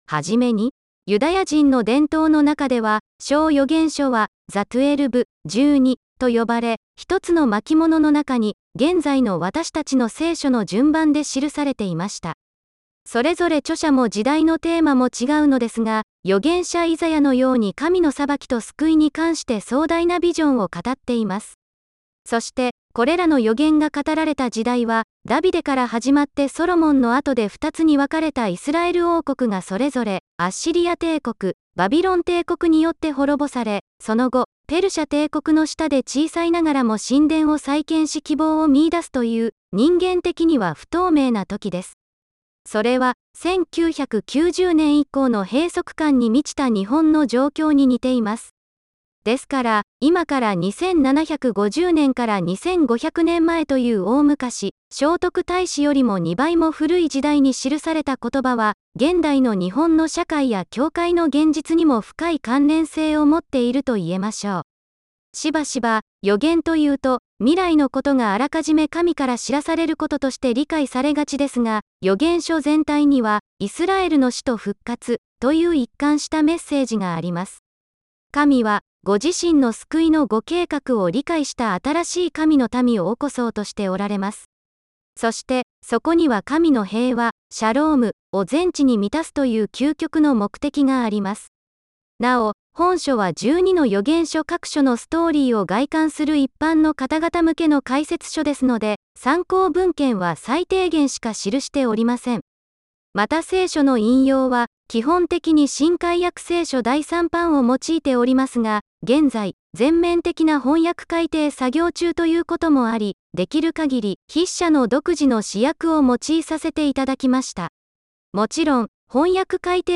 CGNTV Japan「本の旅」インタビュー